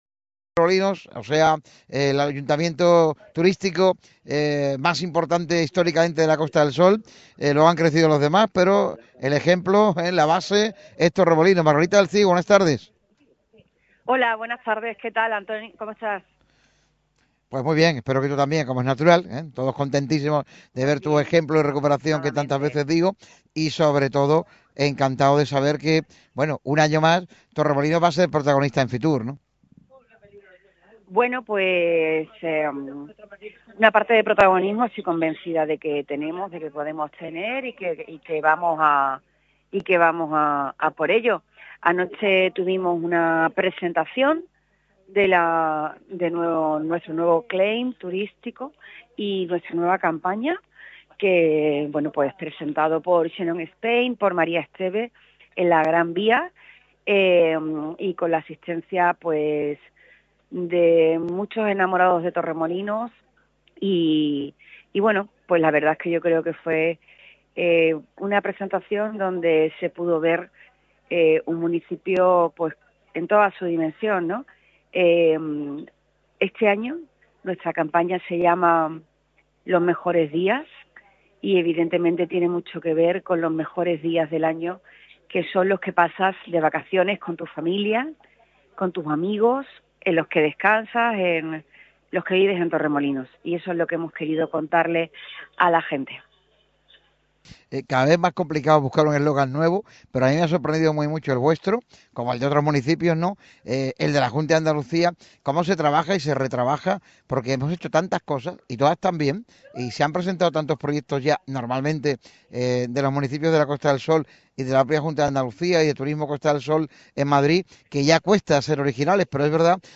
La alcaldesa de Torremolinos, Margarita del Cid, ha tratado el tema de la problemática del agua que tanto afecta a los vecinos y vecinas de la localidad de la Costa del Sol. La dirigente, que se encuentra en FITUR en la capital de España, ha señalado la importancia de la Junta de Andalucía para acatar la sequía.